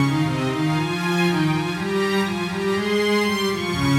Index of /musicradar/80s-heat-samples/120bpm
AM_80sOrch_120-C.wav